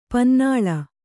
♪ pannāḷa